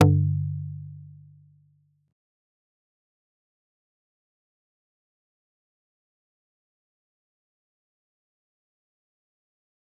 G_Kalimba-G2-pp.wav